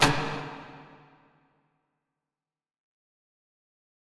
Snare (Amazing).wav